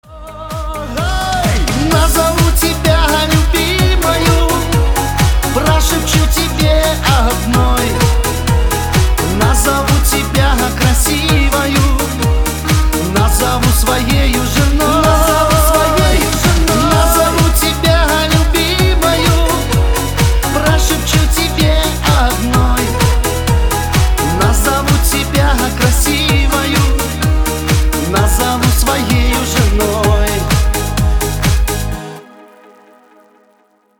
• Качество: 320, Stereo
веселые
русский шансон